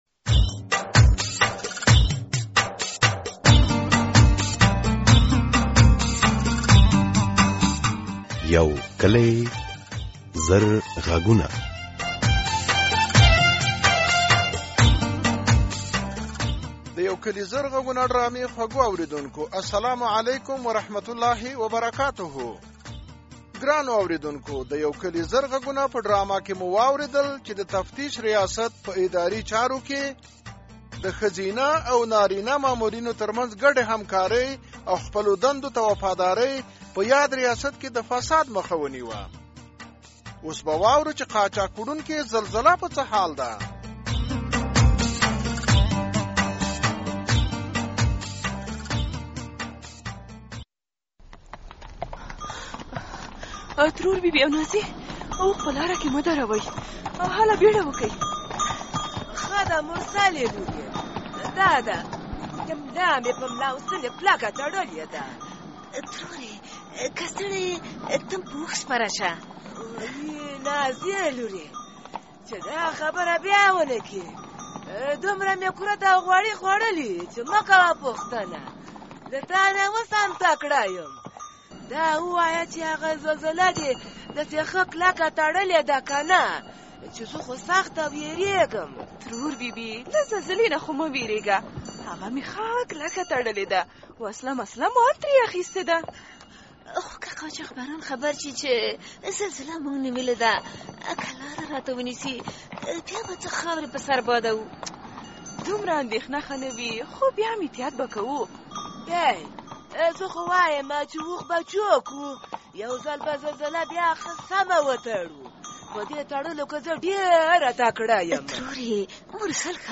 د یو کلي زر غږونو ډرامې ۲۲۱ برخه، د ډرامې په دې برخه کې د نورو موضوعاتو تر څنګ د ښوونې او روزنې په اړه مهم پیغامونه ...